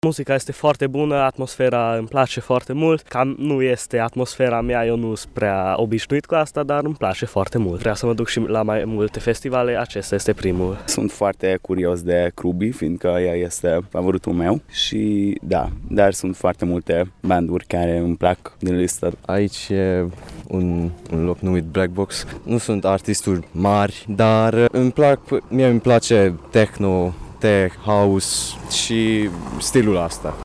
Participanții la festival sunt deja instalați în corturi și așteptă să urce pe scenă. Unii dintre ei sunt pentru prima data la un festival: